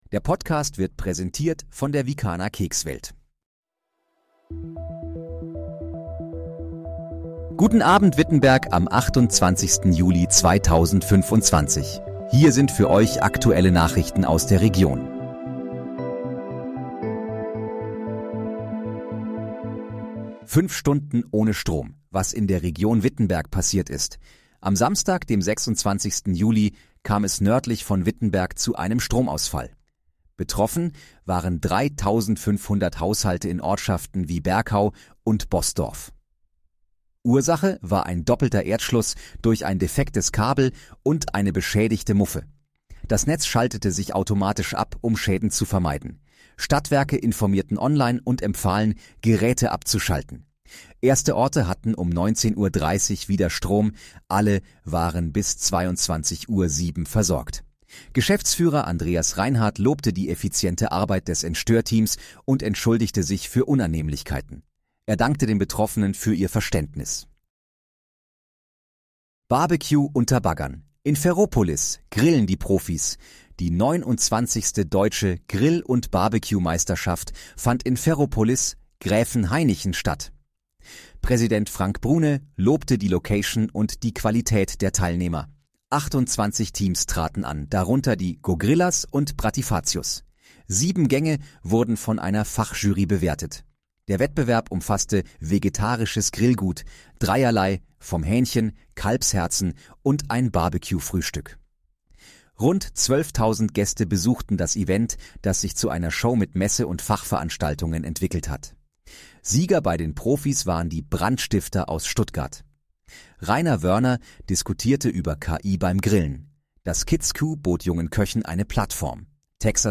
Guten Abend, Wittenberg: Aktuelle Nachrichten vom 28.07.2025, erstellt mit KI-Unterstützung
Nachrichten